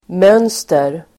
Uttal: [m'ön:ster]